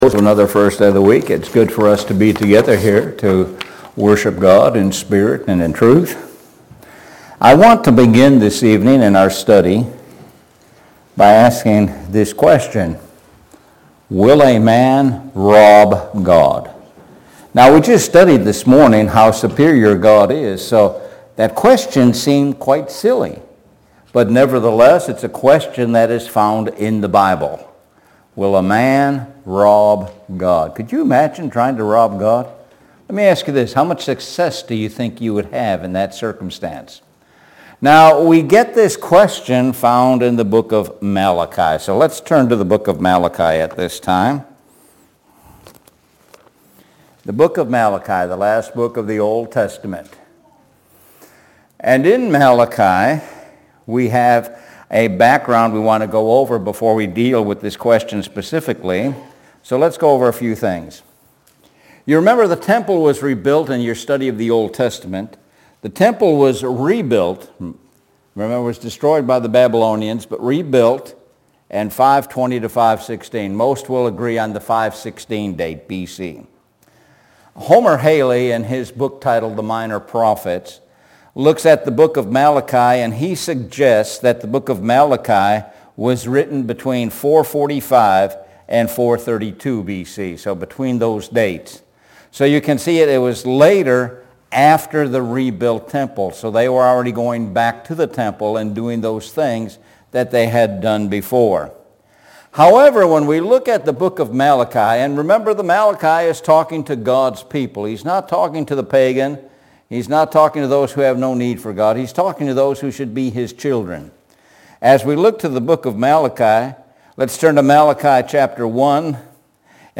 Lakeland Hills Blvd Church of Christ